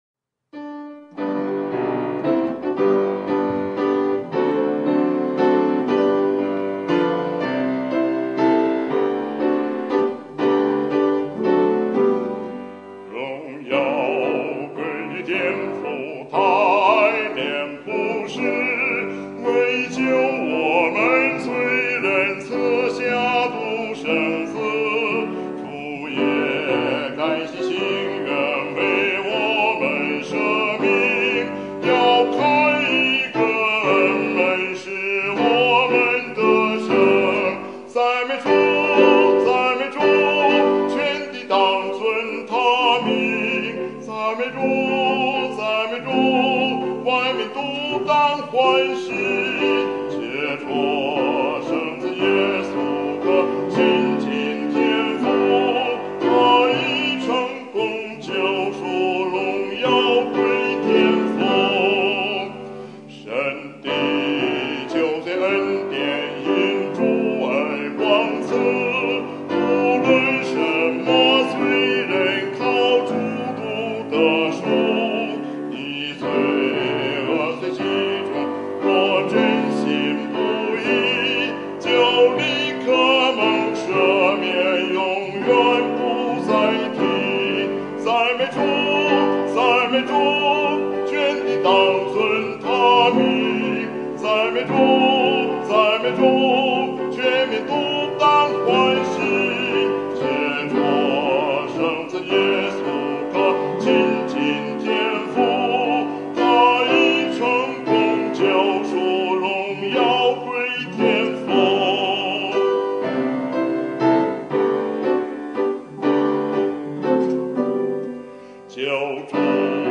赞美诗《荣耀归于天父》